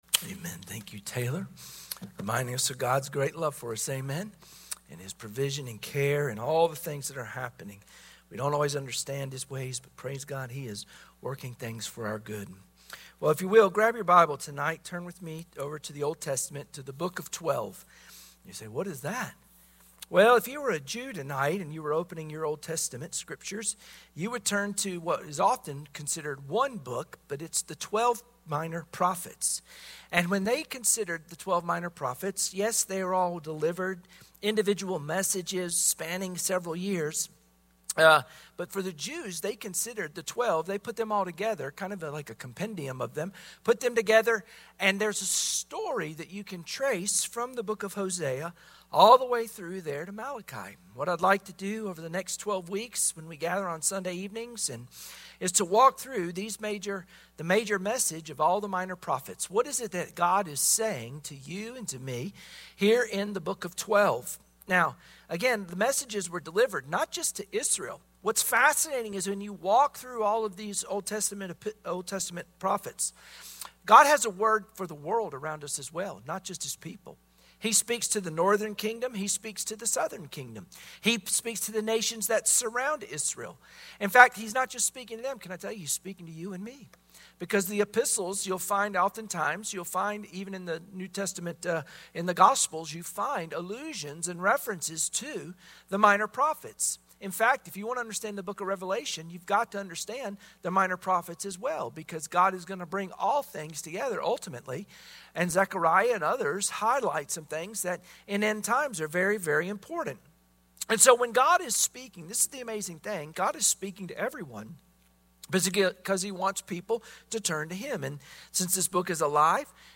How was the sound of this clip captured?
Sunday Evening Service Passage: Hosea Service Type: Sunday Evening Worship Share this